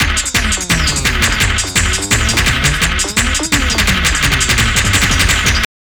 __SLOW LFO 1.wav